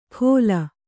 ♪ khōla